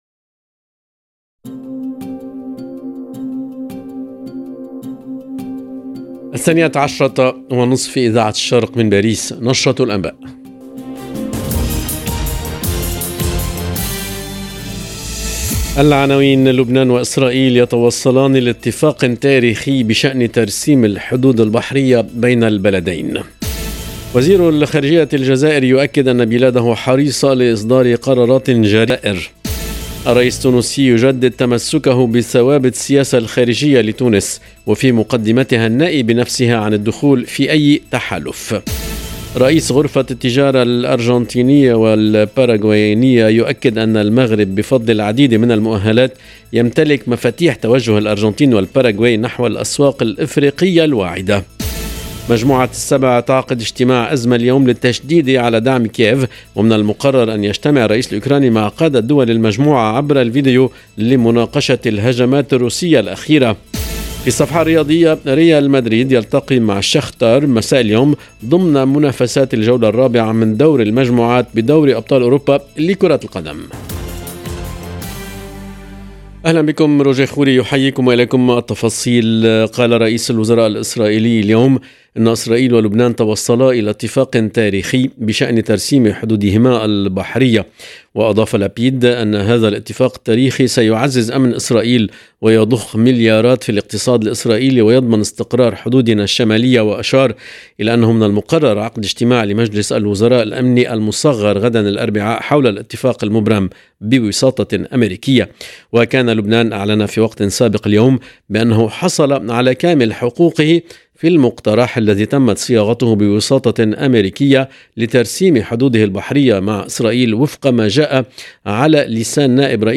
LE JOURNAL EN LANGUE ARABE DU 11/10/22